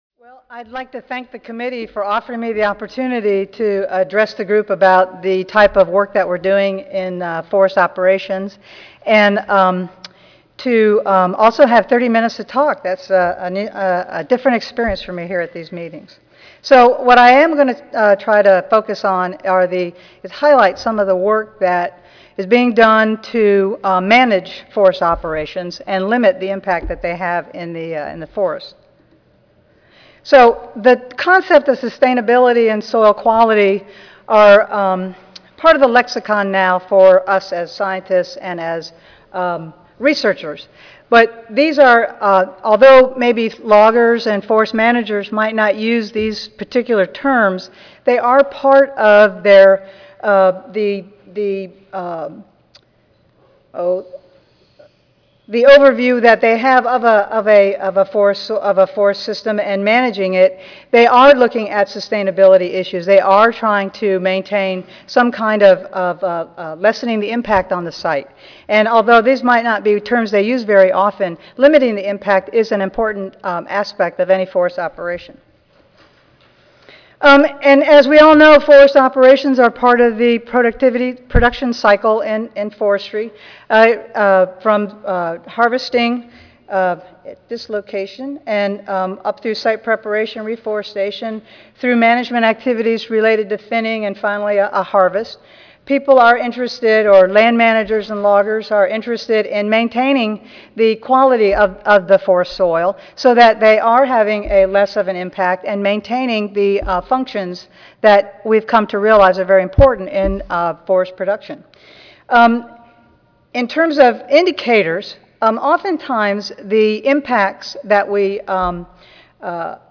AL Audio File Recorded presentation Mechanized forest harvest operations can alter soil properties that have the potential to influence subsequent forest productivity and soil sustainability.